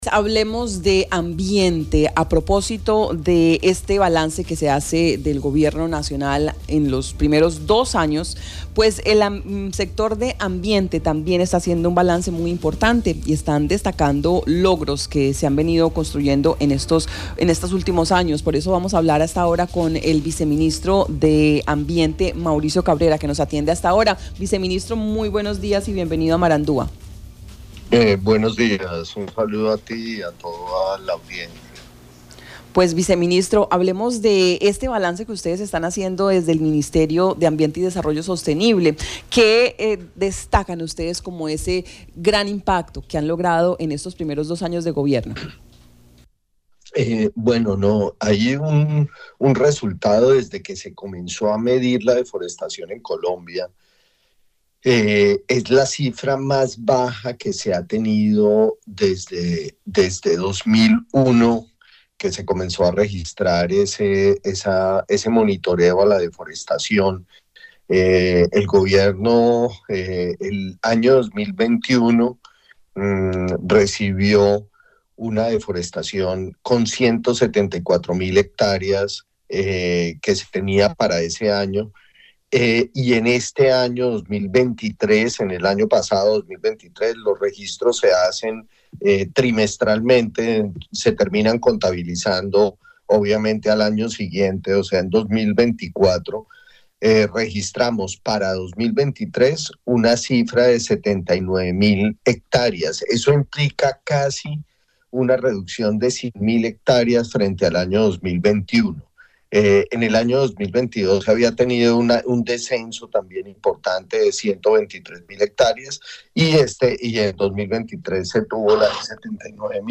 El viceministro de Ambiente, Mauricio Cabrera, en una entrevista con Marandua Noticias, habló sobre el balance emitido por el Ministerio en relación con la disminución de la deforestación en los departamentos de Guaviare, Caquetá y Putumayo.